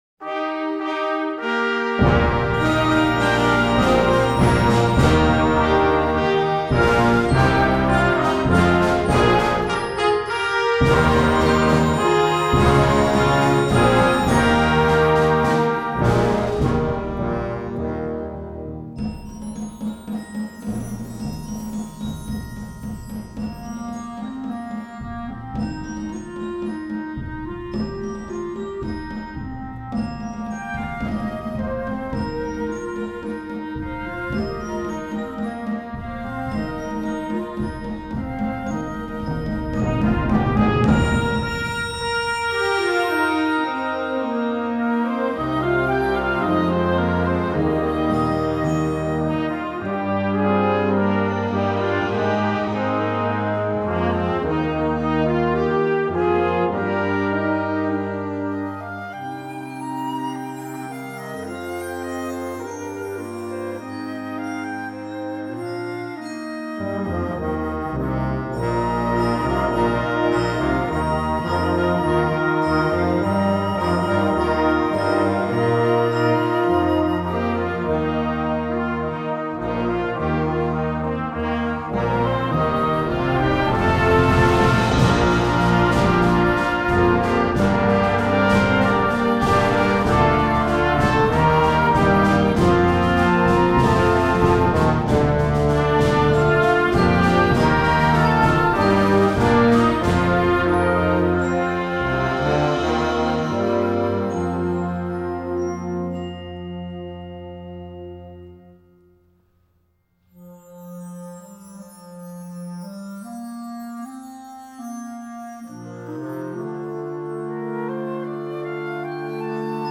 Gattung: Konzertwerk für Jugendblasorchester
Besetzung: Blasorchester
kontrastiert ruhige Passagen mit kühnen Aussagen